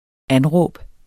Udtale [ ˈanˌʁɔˀb ]